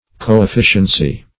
Coefficiency \Co`ef*fi"cien*cy\, n. Joint efficiency; cooperation.